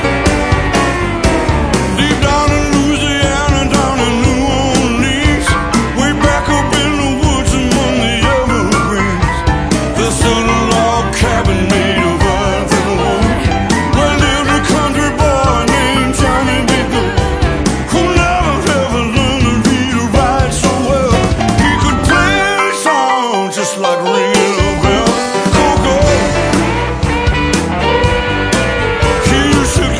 one of the most iconic rock and roll songs of all time
recorded in New York, St. Louis and Los Angeles
piano
lap steel guitar
saxophone